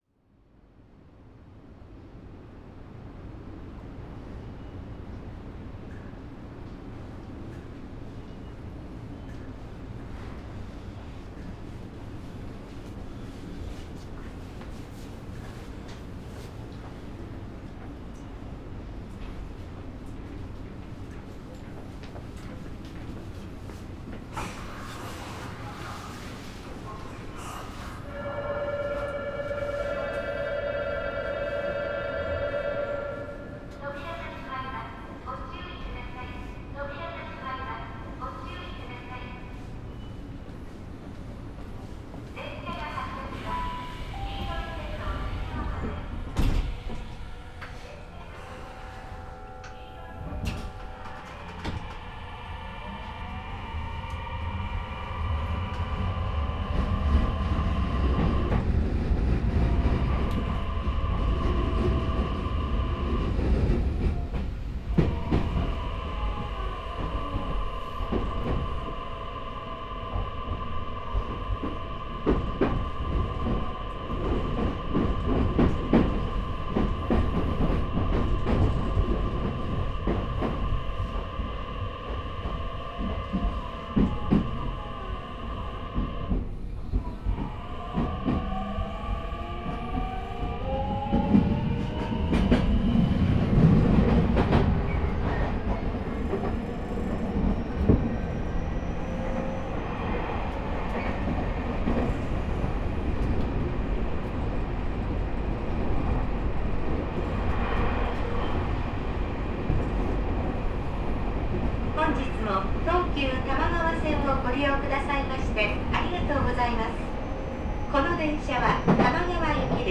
東急電鉄 300系 ・ 走行音(全区間)(その1) (24.7MB★) 収録区間：世田谷線 下高井戸→三軒茶屋 ・ 走行音(全区間)(その2) (28.5MB★) 収録区間：世田谷線 三軒茶屋→下高井戸 制御方式：VVVFインバータ制御(三菱2レベルIGBT) 1999(平成11)年に登場した世田谷線用の車両。